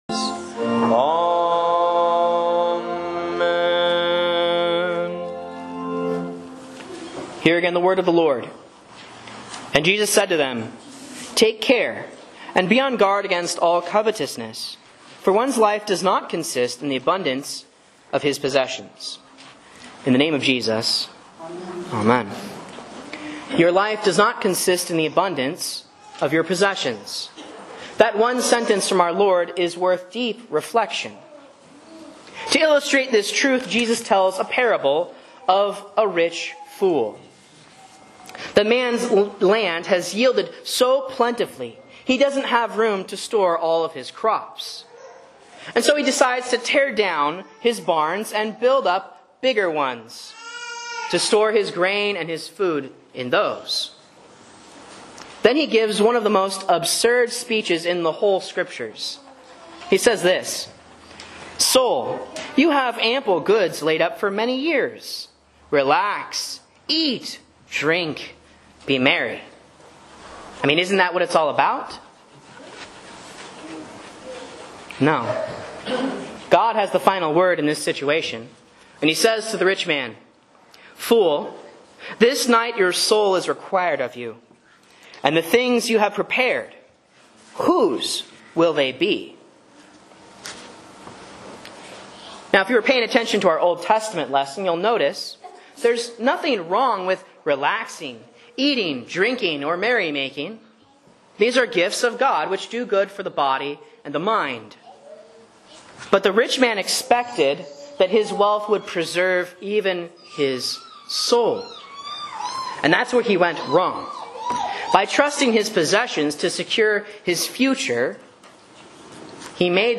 Sermons and Lessons from Faith Lutheran Church, Rogue River, OR
A Sermon on Luke 12:15 for Proper 13 (C)